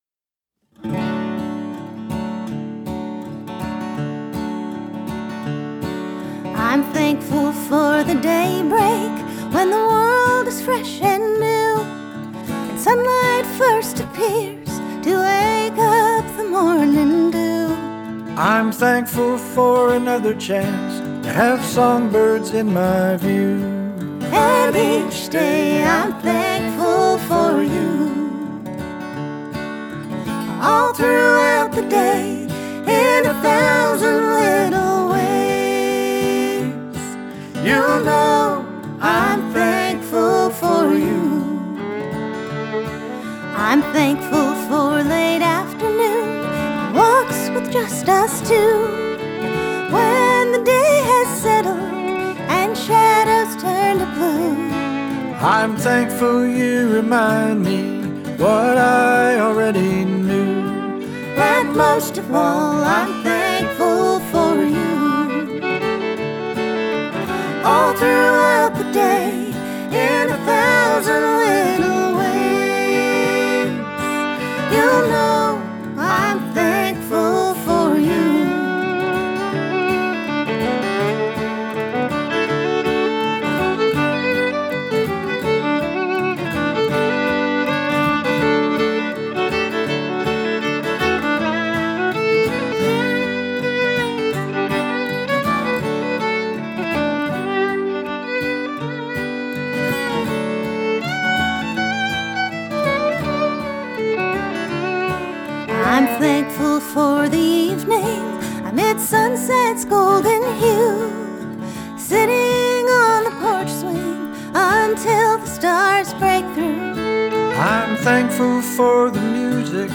fiddle
guitar and banjo